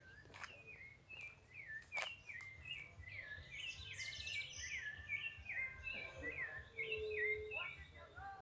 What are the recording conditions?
Province / Department: Entre Ríos Detailed location: Villa Zorraquin Condition: Wild Certainty: Observed, Recorded vocal